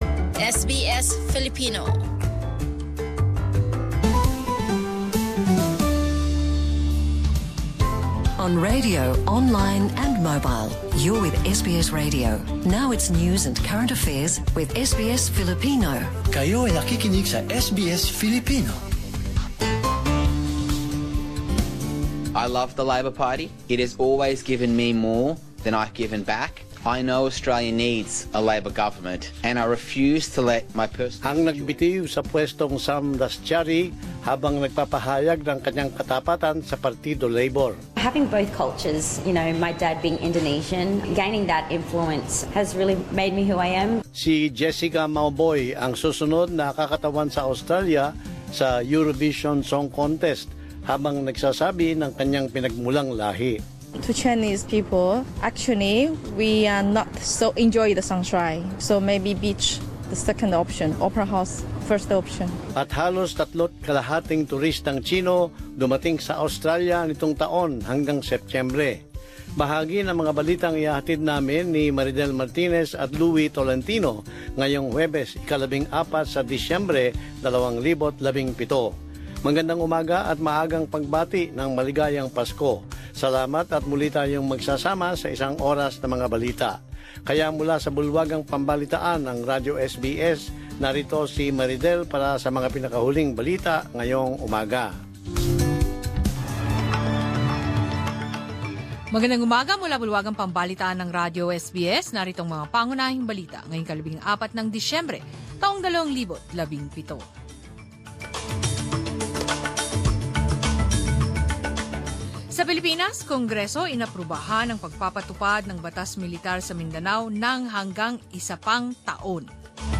10 am News Bulletin